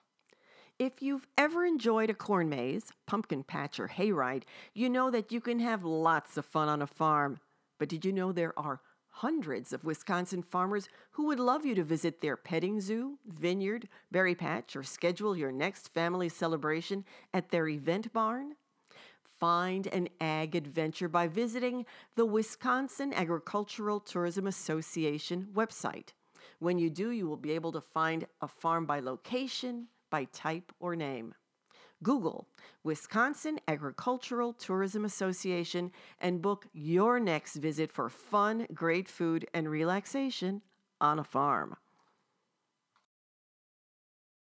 Wisconsin Agricultural Tourism – Public Service Announcements (PSAs)